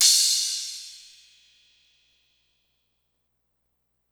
Crashes & Cymbals
Crash [Signature].wav